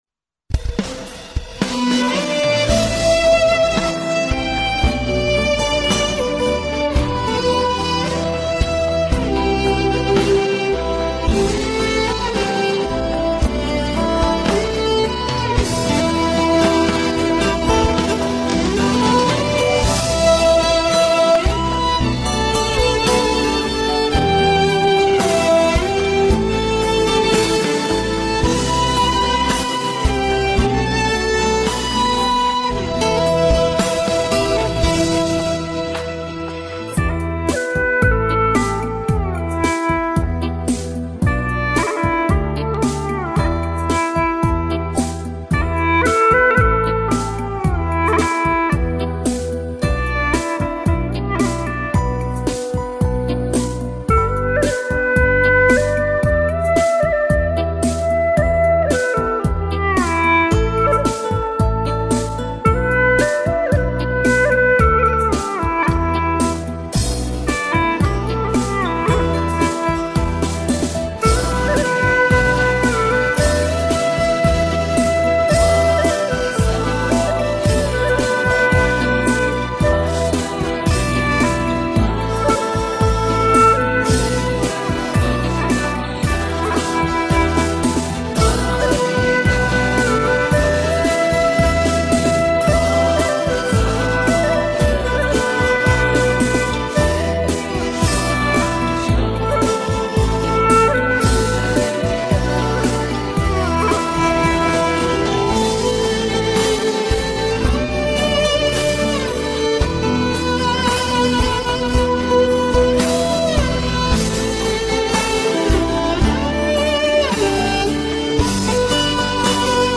调式 : G 曲类 : 流行